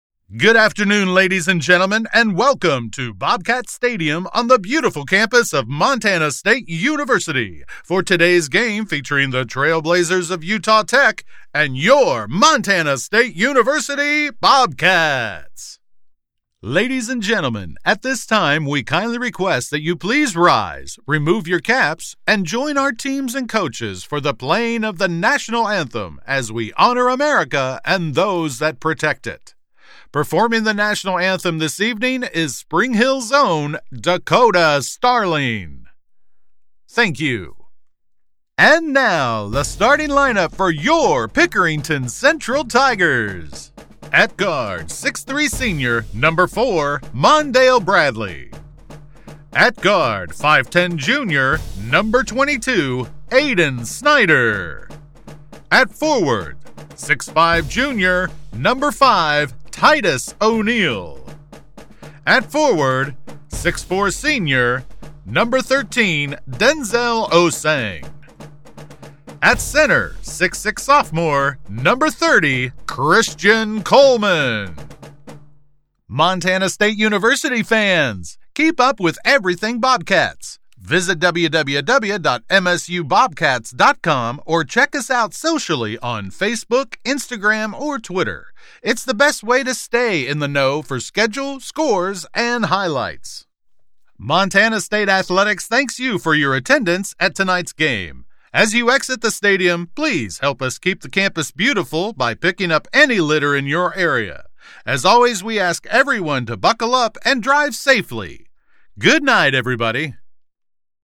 PA Announcer
PA-Announcer.mp3